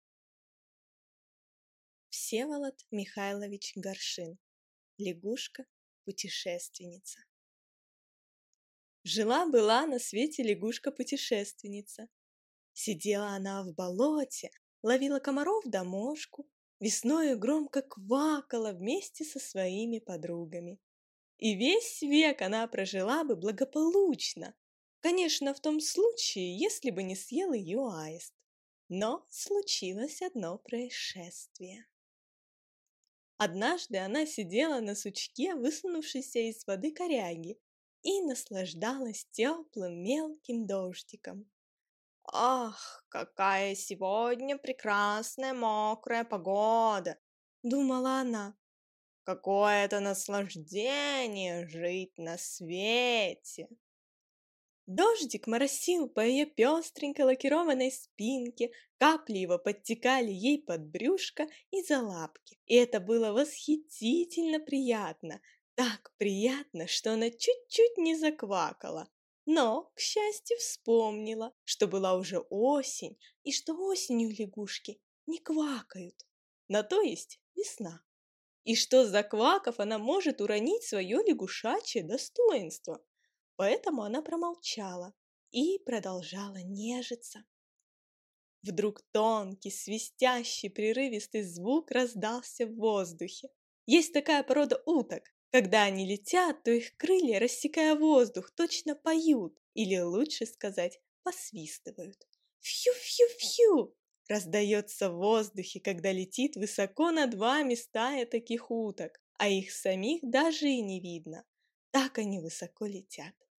Аудиокнига Лягушка-путешественница | Библиотека аудиокниг